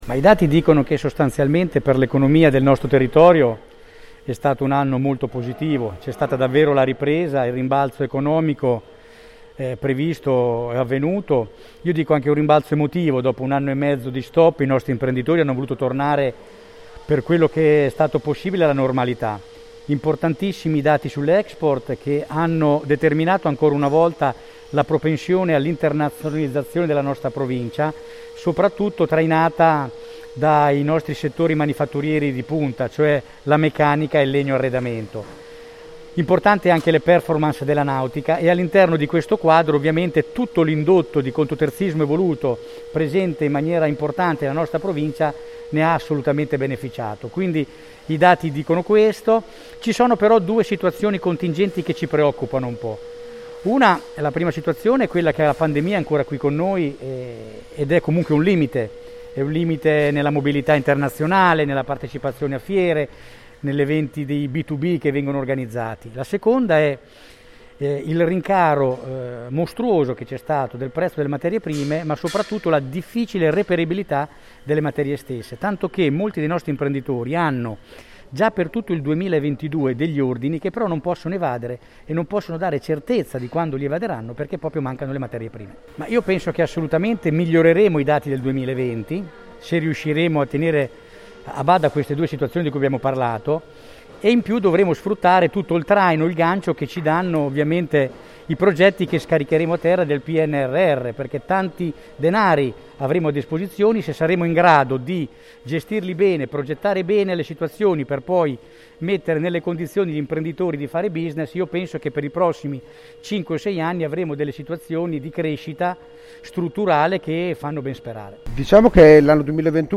Nella sede di Biemmegi Meccanica di Pesaro. si è tenuto l’incontro di Cna Pesaro e Urbino, dove si è fatto il bilancio sui dati dell’economia per il 2021. Il quadro emerso parla di un anno più che incoraggiante alle voci export, ricavi e occupazione.